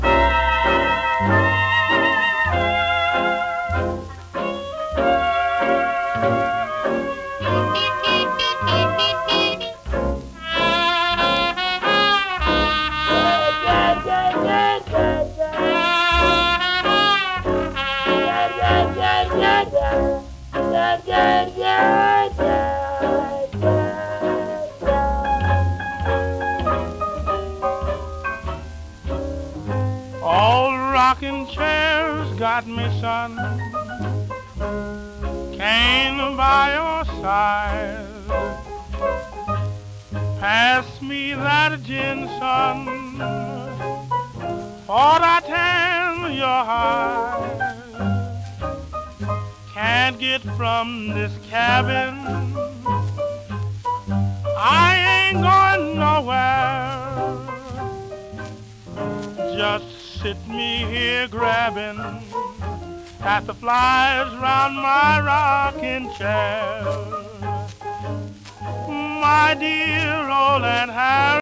1930年頃の録音